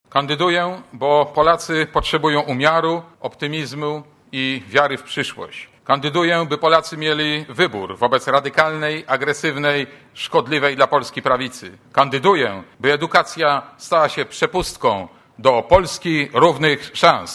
* Mówi Marek Borowski*